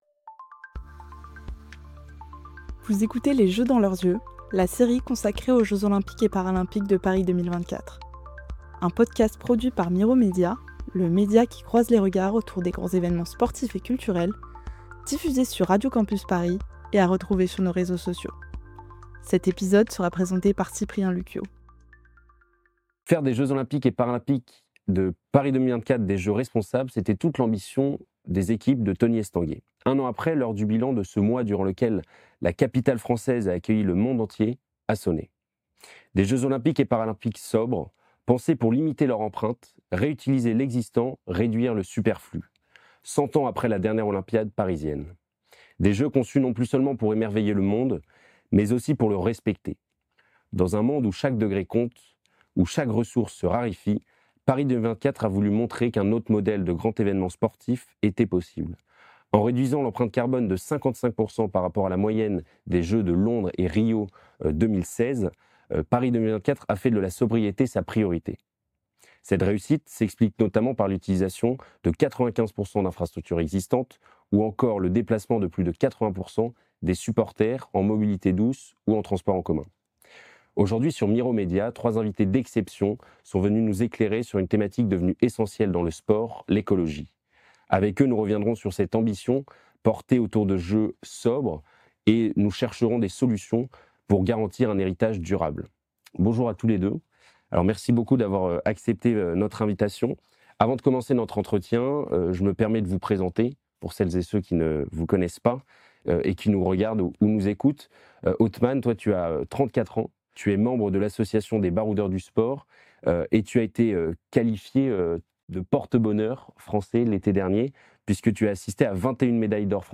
Les Jeux dans leurs Yeux - Épisode 3 : Réduire l'impact Partager Type Entretien Société dimanche 17 août 2025 Lire Pause Télécharger Limiter l’empreinte écologique du sport, c’est possible … et essentiel.